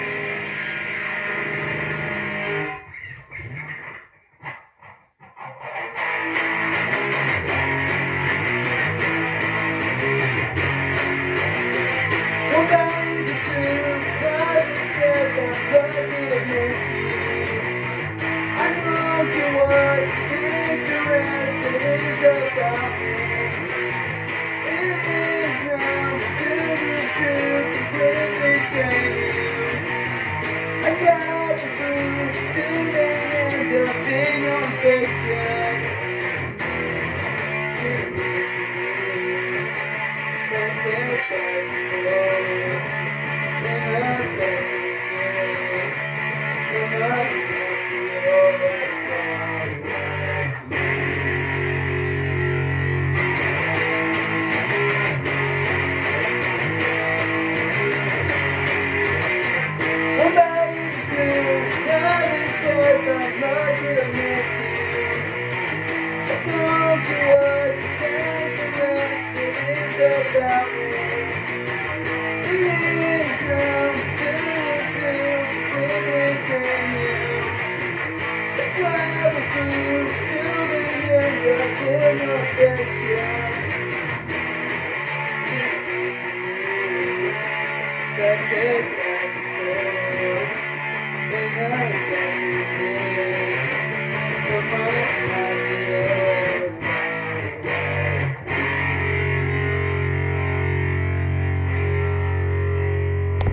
sorry for bad audio again😭🙏